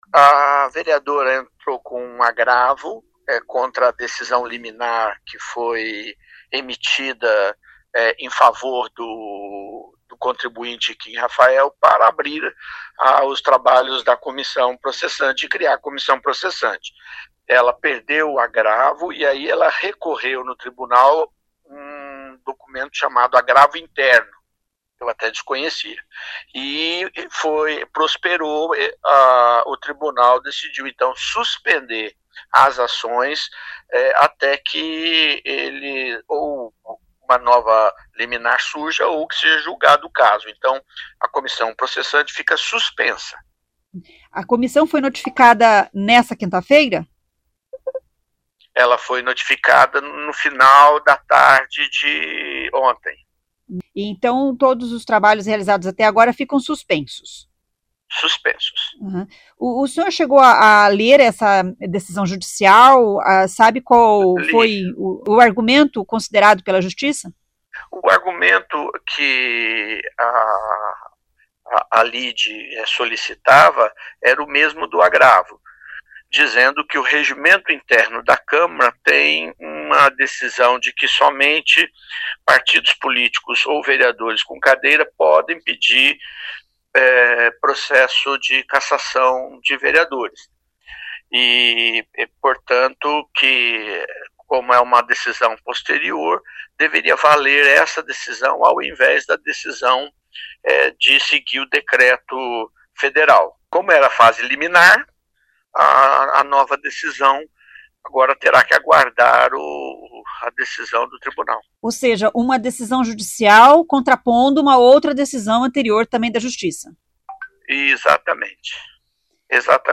O relator Sidnei Telles diz que a comissão foi notificada da decisão nessa quinta-feira (29):